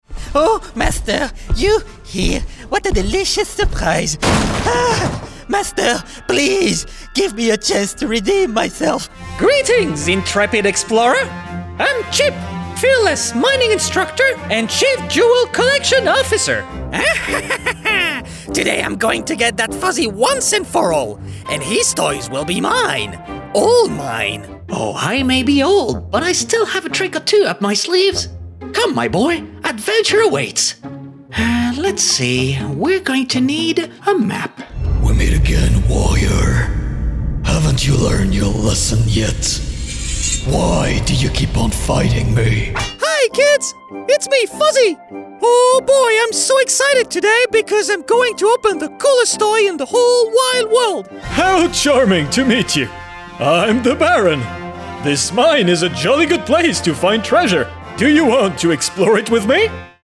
Male
Assured, Authoritative, Character, Confident, Cool, Corporate, Engaging, Friendly, Gravitas, Natural, Reassuring, Smooth, Warm, Versatile, Soft
Microphone: Audio Technica AT2020 USB+
Audio equipment: Sound-treated booth, Beyerdynamic DT250 headphones, SourceConnect Now, ipDTL, Skype